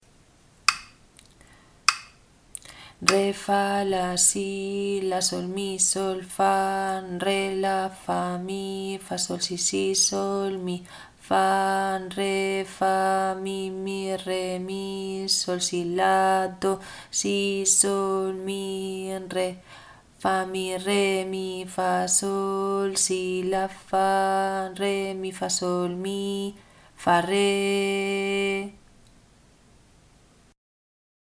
ritmo3_4_repaso.mp3